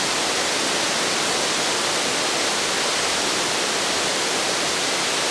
waterfall.wav